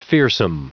Prononciation du mot fearsome en anglais (fichier audio)
Prononciation du mot : fearsome